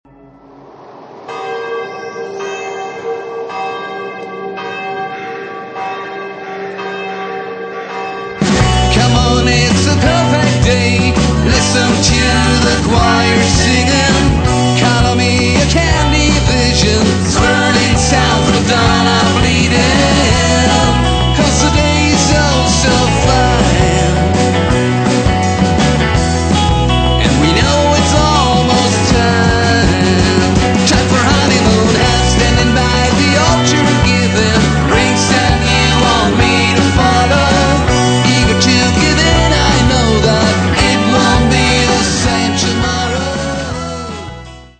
exciting fast voc.